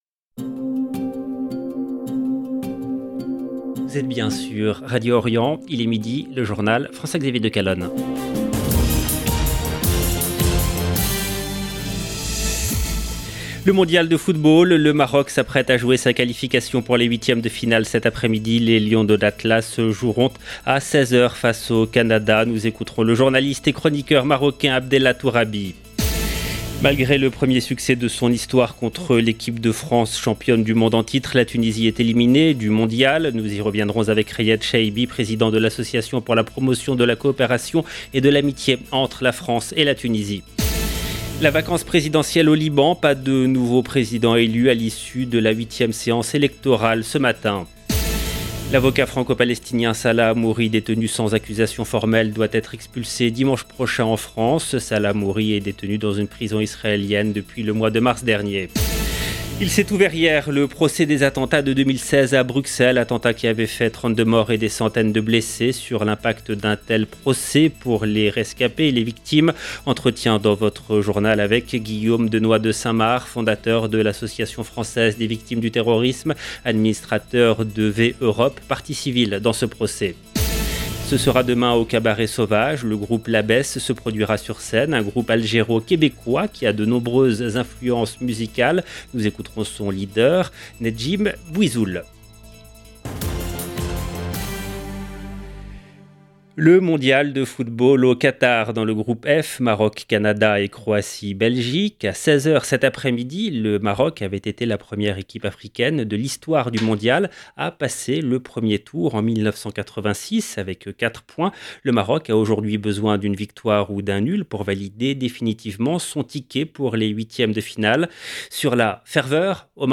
EDITION DU JOURNAL DE 12 H EN LANGUE FRANCAISE DU 1/12/2022